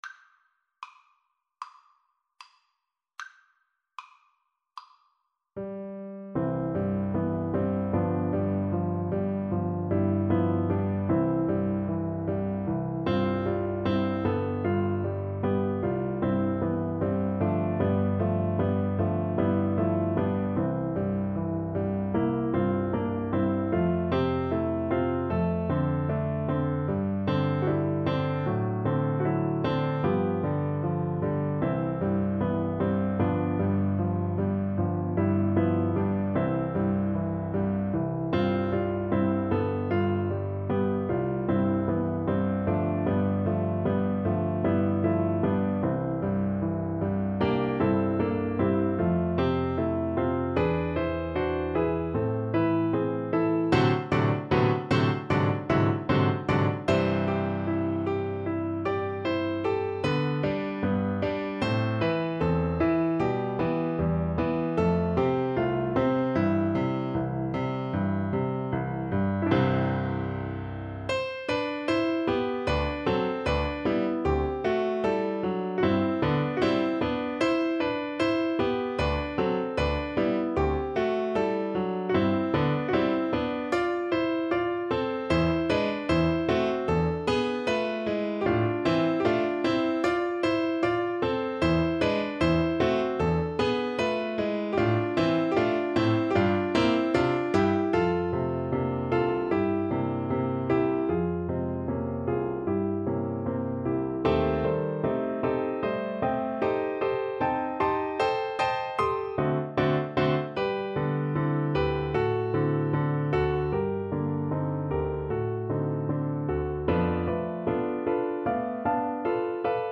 2/4 (View more 2/4 Music)
Lento ma non troppo = c.76
Classical (View more Classical Bassoon Music)